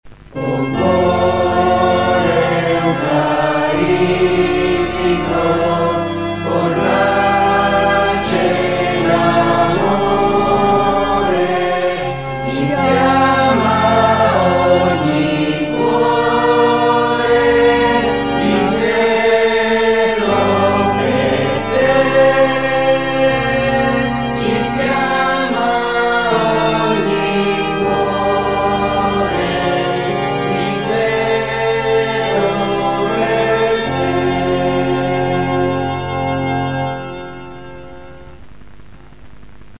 Giaculatoria cantata